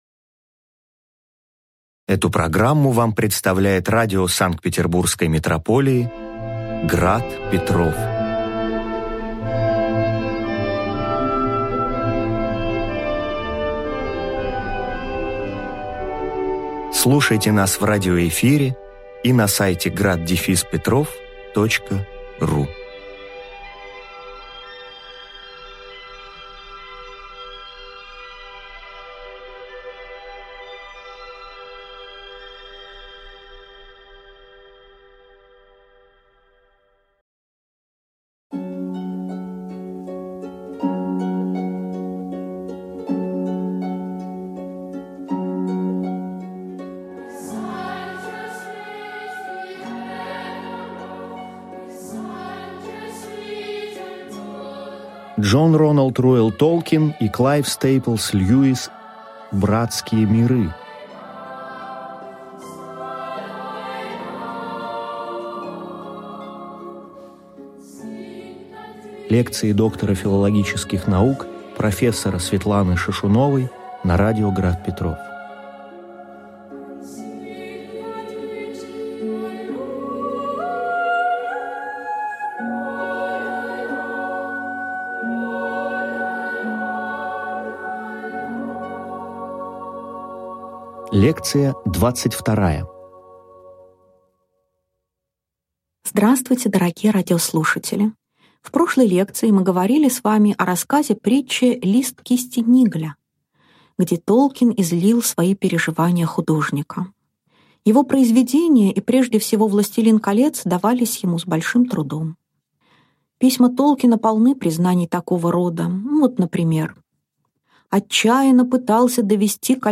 Аудиокнига Лекция 22. К.С.Льюис. Аллегорическая повесть «Возвращение паломника» | Библиотека аудиокниг